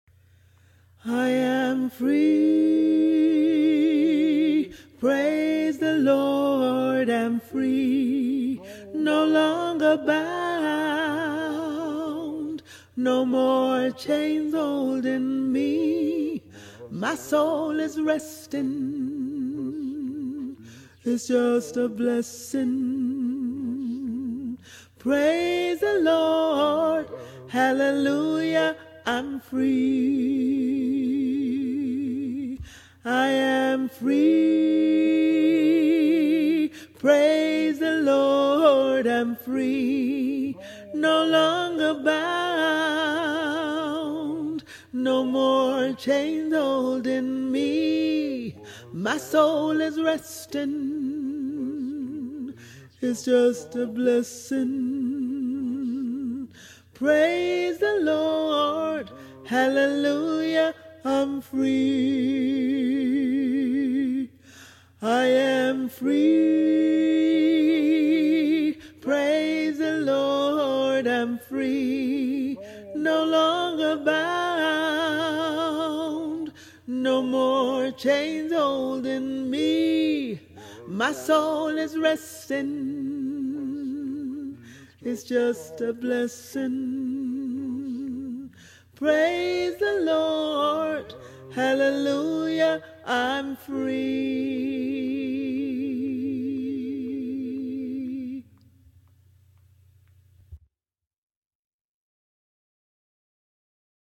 I am Free – Tenor
Genre: Choral.
I-am-Free-All-Tenor.mp3